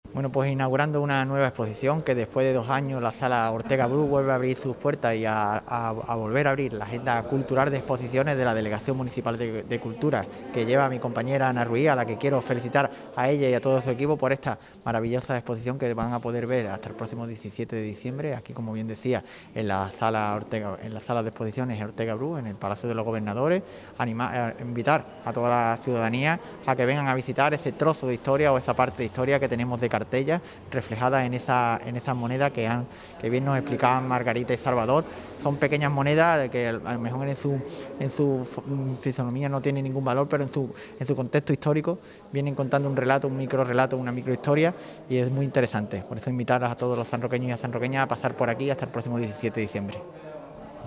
Inaugurada en la galería del Palacio la exposición “Pecvnia”, sobre monedas desde época romana